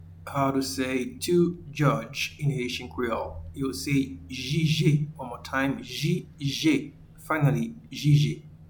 Pronunciation and Transcript:
to-Judge-in-Haitian-Creole-Jije.mp3